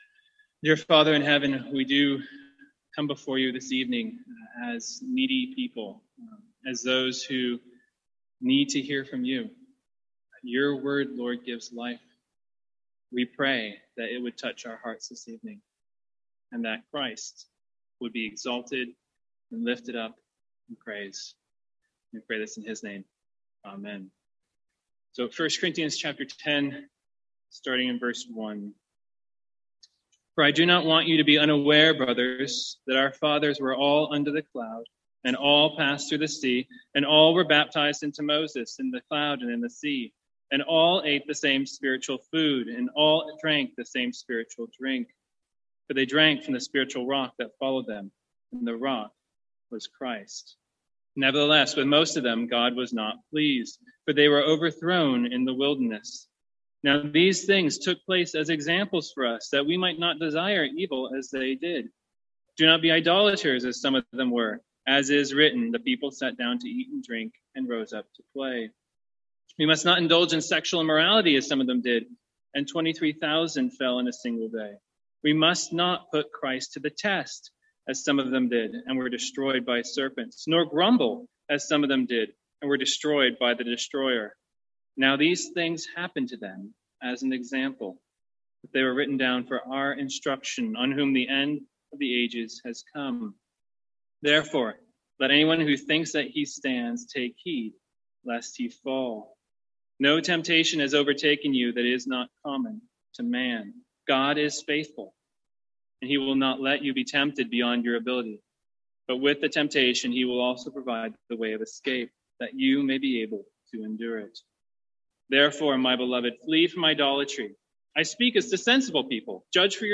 Sermons | St Andrews Free Church
From our evening series in 1 Corinthians.